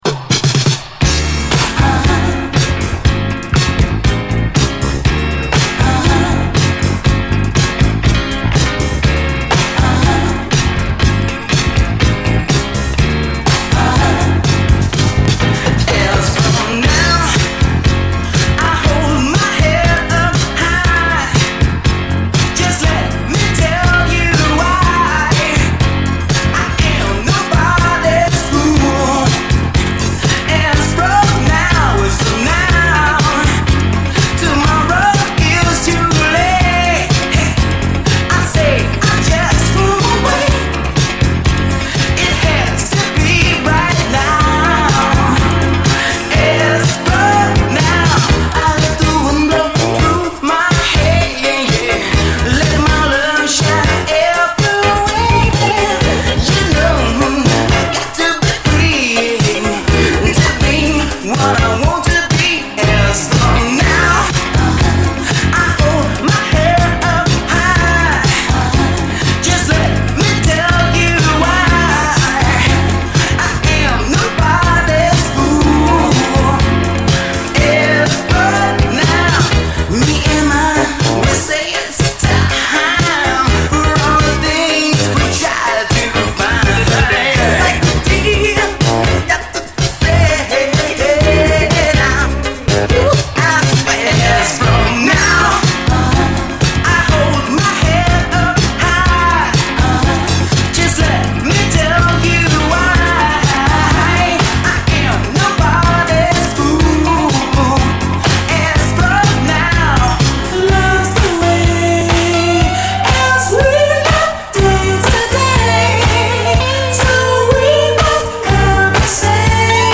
SOUL, 70's～ SOUL, DISCO
最高アーバン・ブギー！
こちらも負けず劣らずの最高ブギー！ド頭からアーバンさが炸裂！